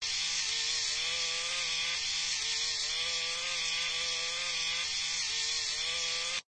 robot_move.ogg